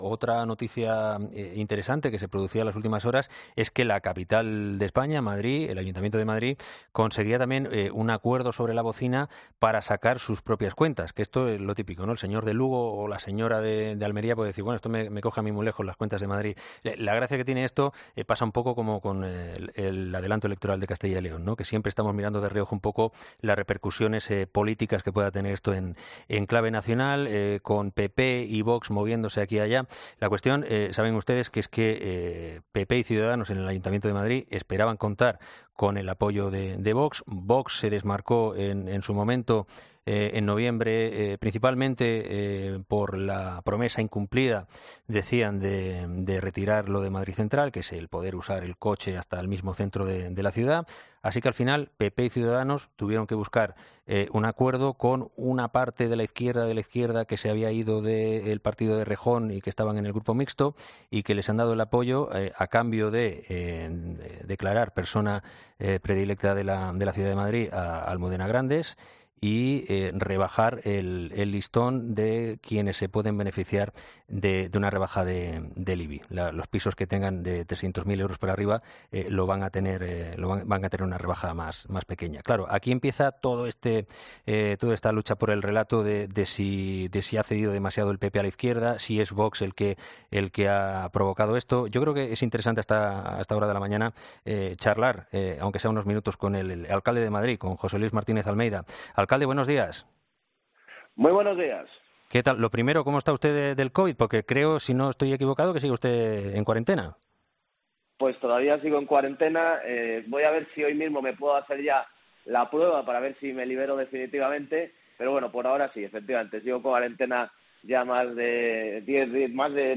En una entrevista este martes en "Herrera en COPE", el regidor madrileño ha defendido las concesiones que ha tenido que hacer para aprobar las cuentas de 2022.